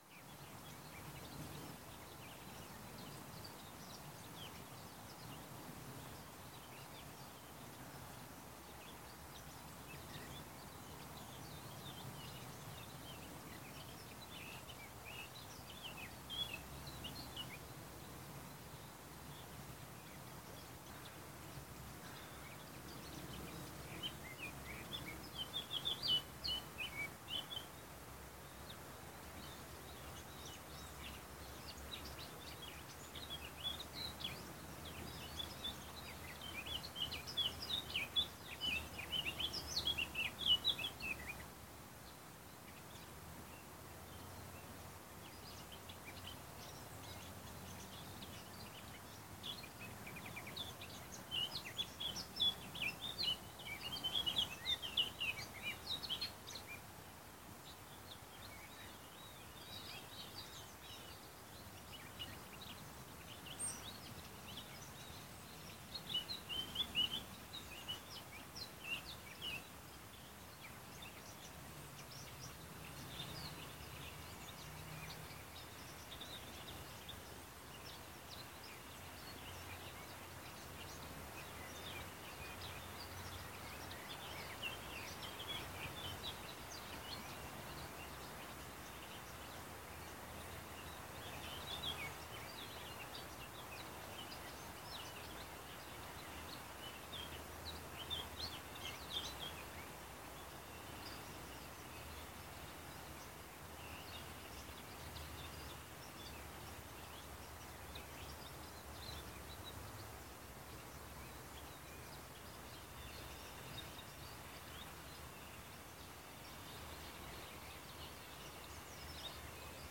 城市公园（葡萄牙波尔图） " 城市公园（葡萄牙波尔图） 11
描述：在城市公园捡到的声音，你可以听到脚步声、声音、风声、汽车、铃声、鸟声、海鸥声。用DIY双耳麦克风套件录制。
Tag: 葡萄牙 ULP-CAM 城市公园 波尔图